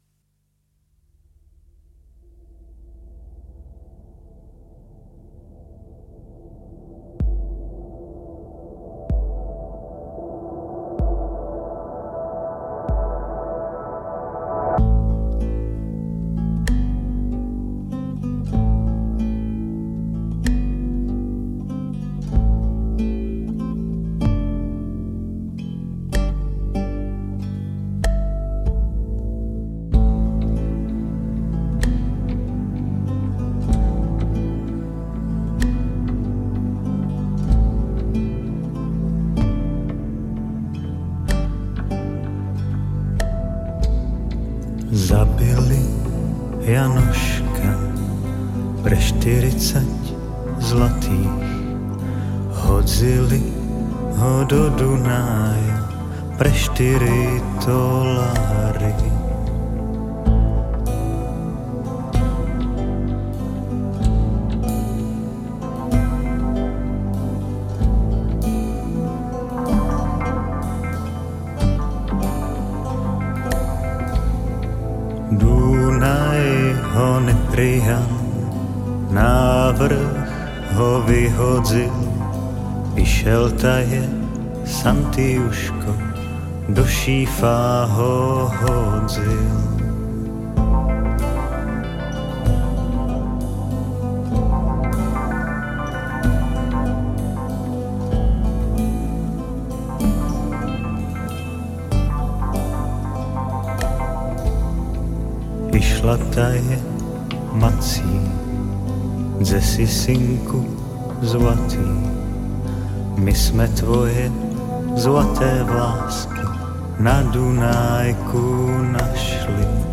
Žánr: World music/Ethno/Folk
bal folk nuevo. tramiditional dance music.
mazurka